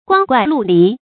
注音：ㄍㄨㄤ ㄍㄨㄞˋ ㄌㄨˋ ㄌㄧˊ
光怪陸離的讀法